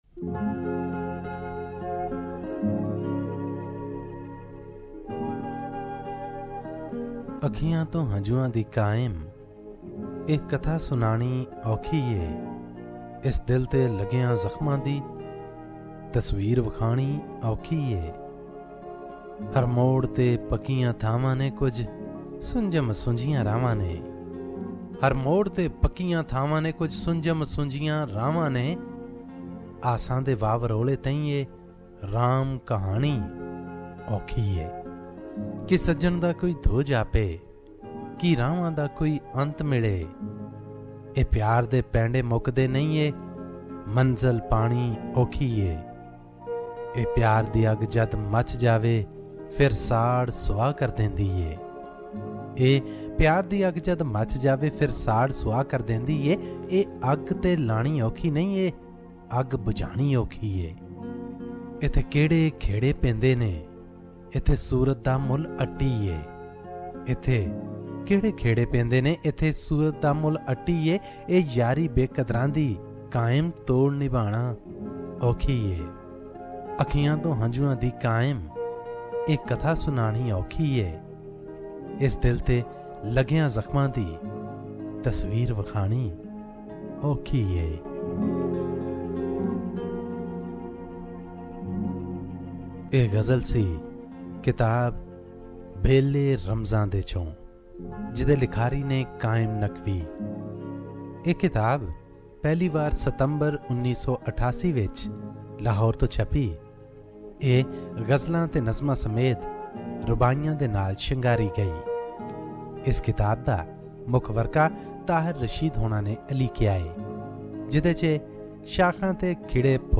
Book Review Balley Ramzan De by Qaim Naqvi